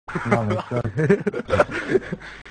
Worms speechbanks
wobble.wav